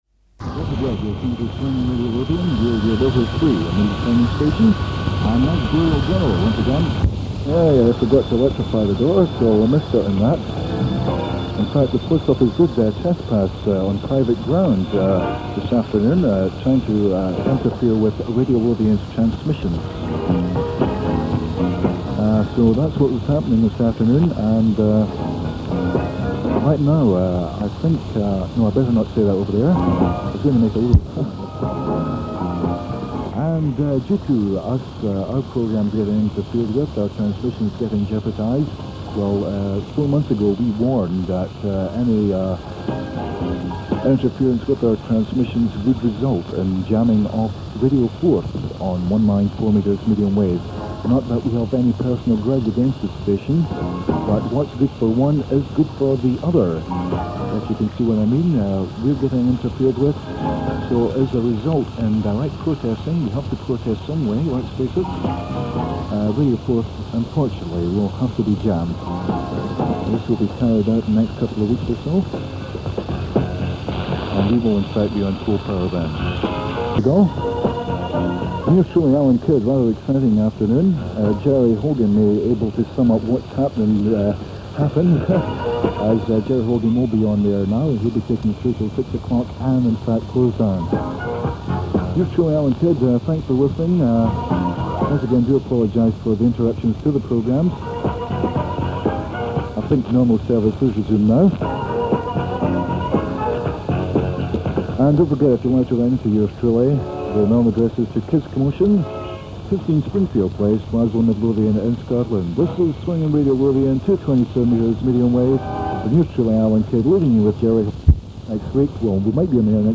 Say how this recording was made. This airchecked recording of 227m, was made from the 6220kHz shortwave relay on the afternoon of Sunday 16th March 1975.